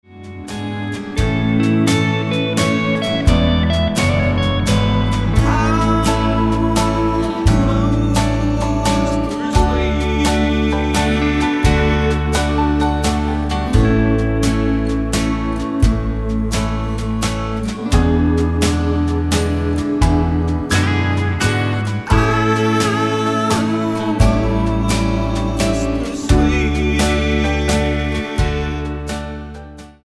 Tonart:A-B mit Chor